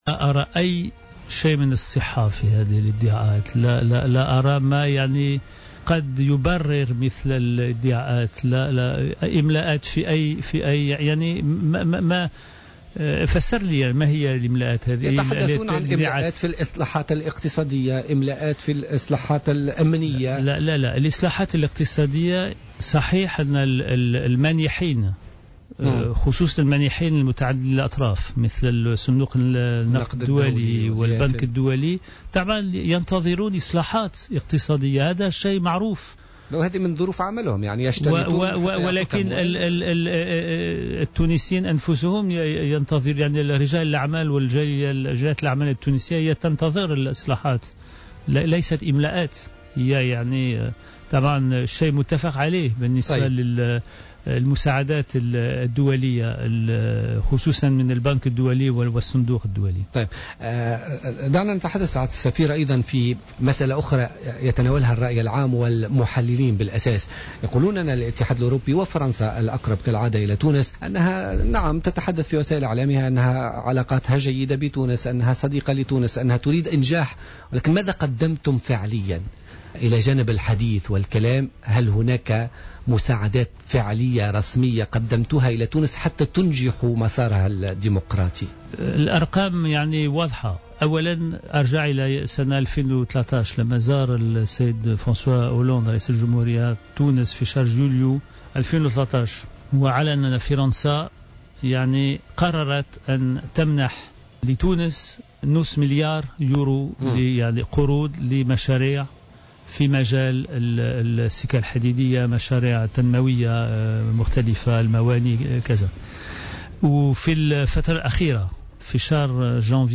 وقال غويات في حوار مع الجوهرة أف أم، اليوم الخميس، إن صندوق النقد الدولي والبنك الدولي ينتظران إصلاحات اقتصادية التزمت تونس بتنفيذها وهي إصلاحات ينتظرها أيضا رجال الأعمال والمواطنون التونسيون، ولا يمكن وصفها بالإملاءات، على حد قوله.